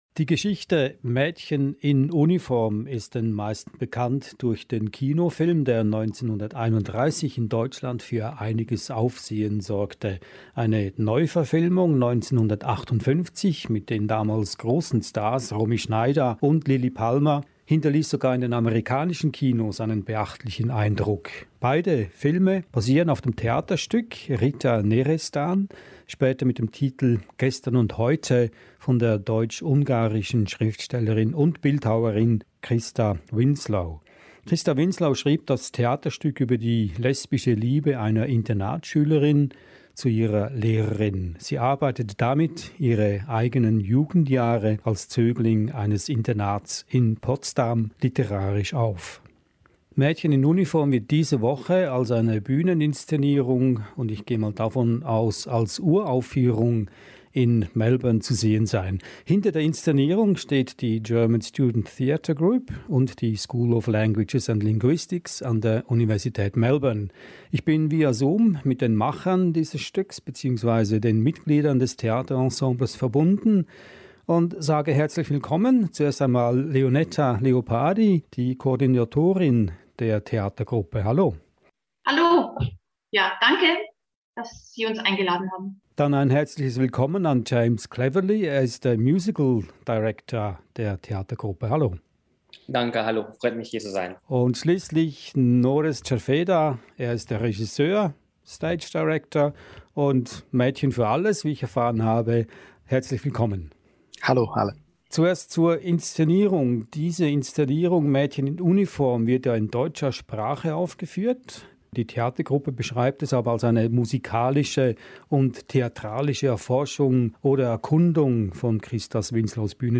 In the interview we speak to the coordinator of the theater group